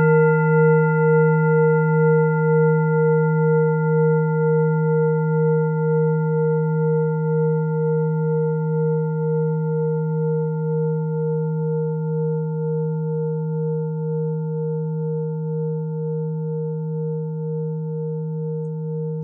Klangschale TIBET Nr.24
Klangschale-Gewicht: 1110g
Klangschale-Durchmesser: 21,1cm
Sie ist neu und ist gezielt nach altem 7-Metalle-Rezept in Handarbeit gezogen und gehämmert worden..
(Ermittelt mit dem Filzklöppel)
Wasserstoffgamma Frequenz
klangschale-tibet-24.wav